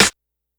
Snares
snr_04.wav